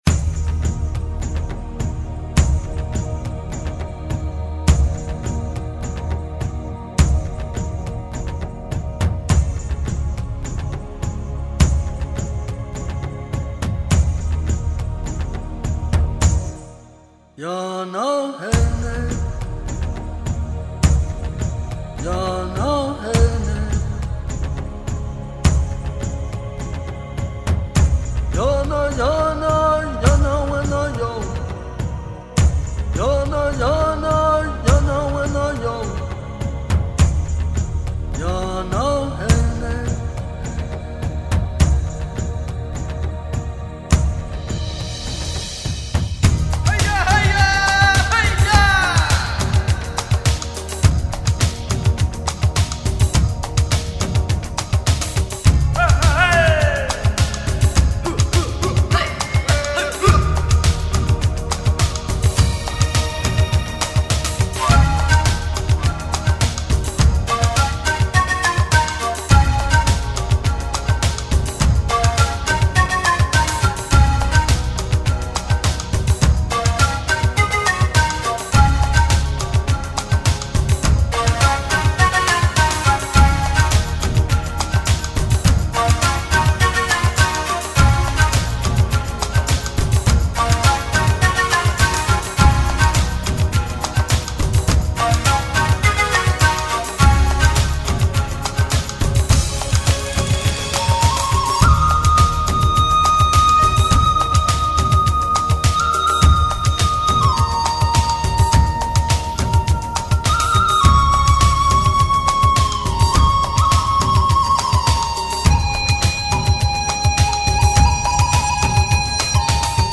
New Age Музыка шаманов Песни шаманов